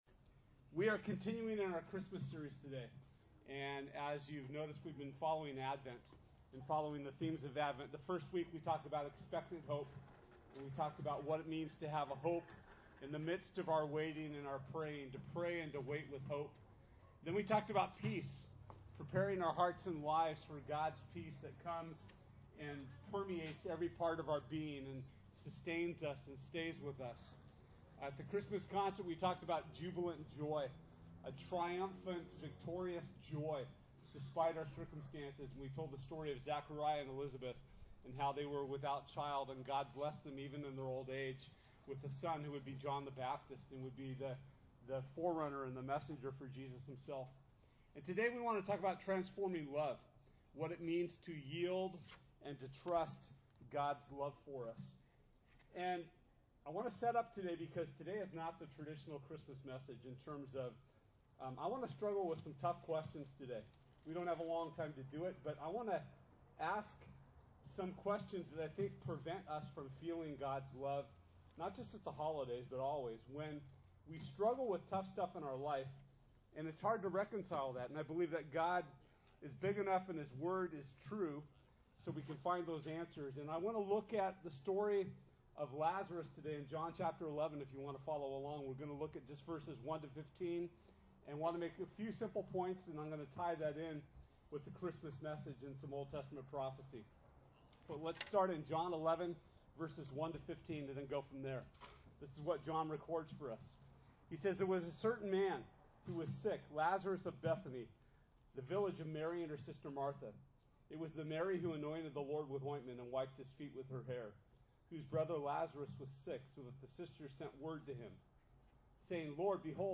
John 11:1-15 Service Type: Sunday This Sunday we’ll be celebrating the theme of LOVE as we continue Advent.